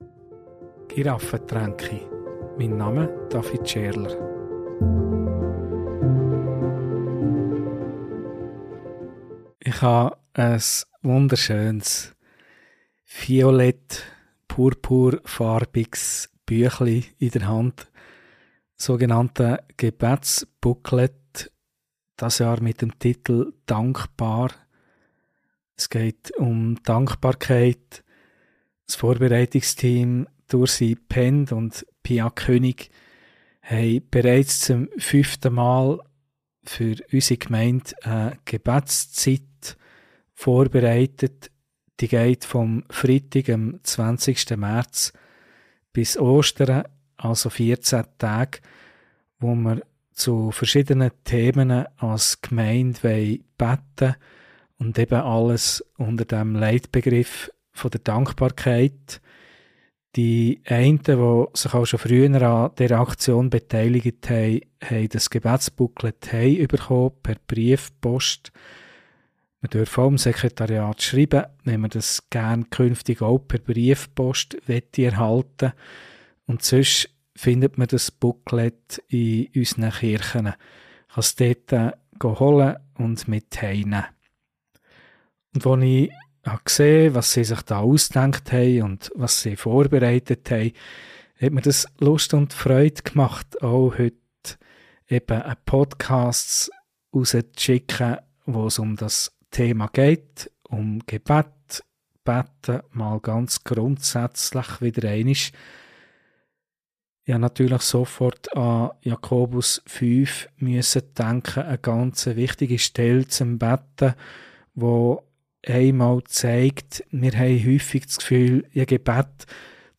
Der Jakobusbrief zeigt: Es geht nicht nur um Gesundheit, sondern um Versöhnung, Gebet und ein Leben vor Gott. Eine Predigt über Krankheit und Gebet, Vergebung und Heilung; und darüber, was es bedeutet, im Namen des Herrn zu beten.